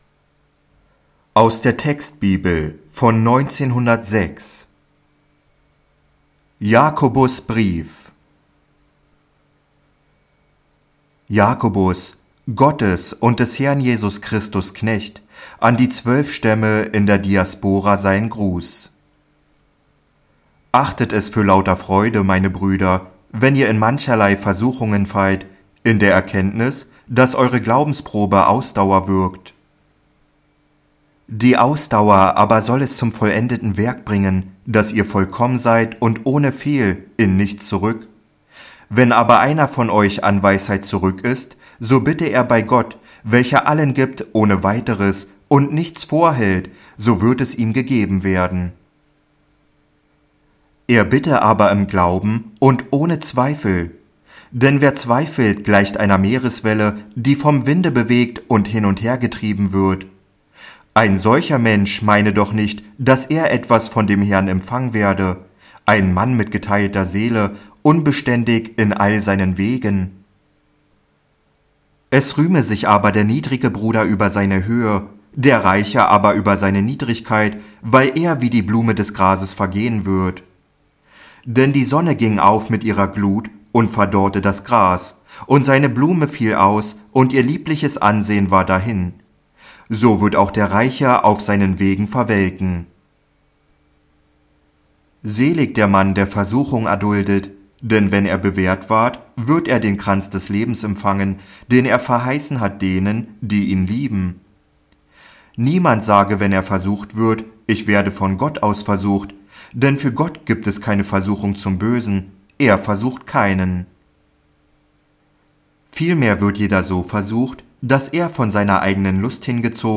▷ Jakobusbrief | Textbibel als MP3-Hörbuch (1906) ✅